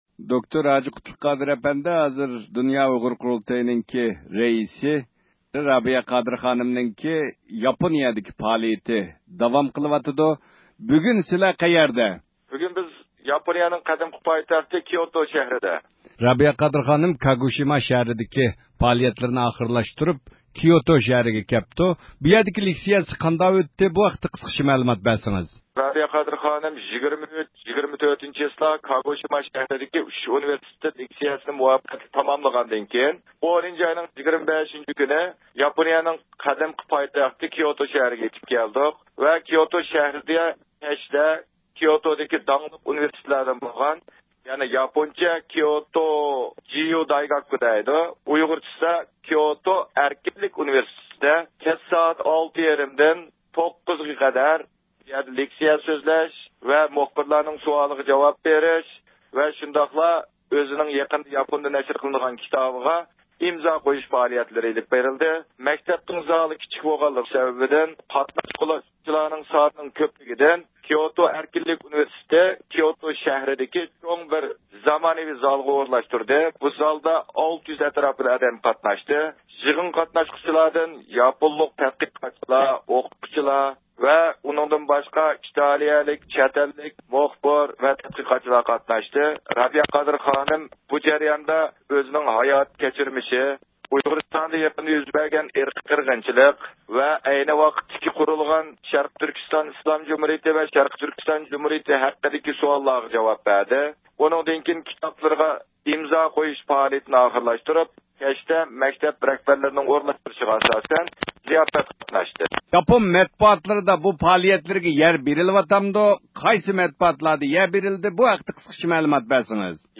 بىز بۇ ھەقتە تەپسىلىي مەلۇمات ئېلىش ئۈچۈن نەق مەيدانغا تېلېفون قىلىپ، رابىيە قادىر خانىم ۋە باشقىلار بىلەن تېلېفون زىيارىتى ئېلىپ باردۇق.